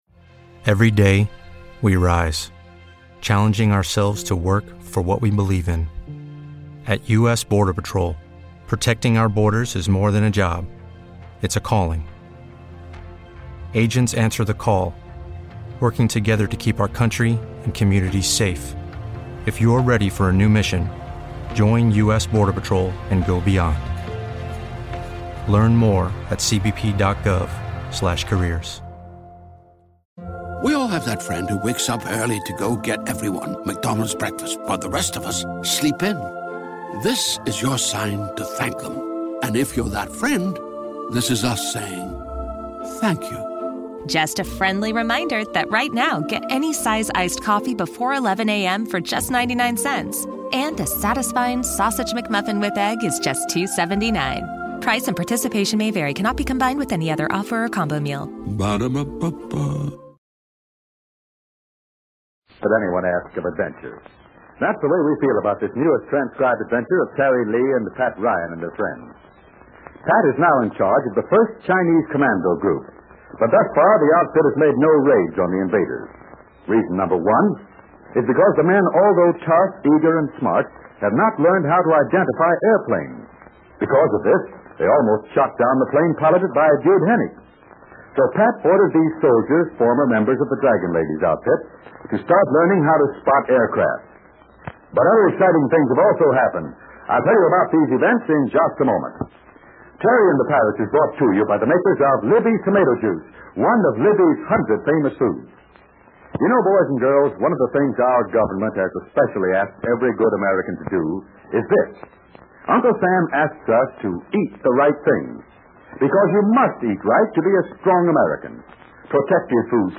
Terry and the Pirates was an American radio serial adapted from the comic strip of the same name created in 1934 by Milton Caniff. With storylines of action, high adventure and foreign intrigue, the popular radio series enthralled listeners from 1937 through 1948.